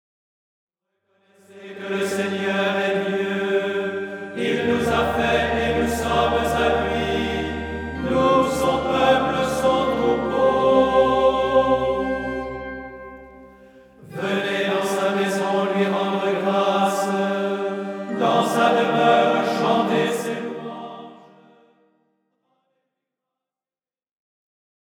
Hymnes & Tropaires
Format :MP3 256Kbps Stéréo